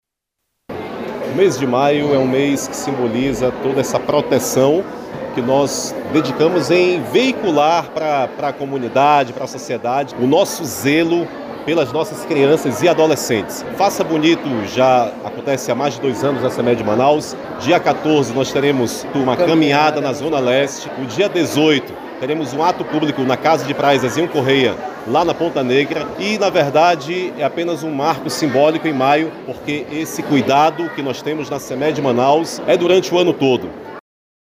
O secretário de Educação da capital, Júnior Mar, destaca as atividades a serem realizadas neste mês.